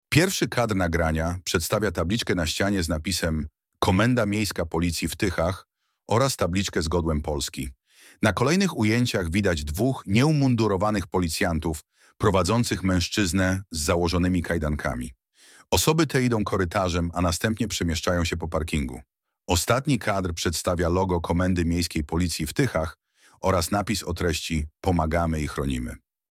Nagranie audio Audiodeskrypcja do nagrania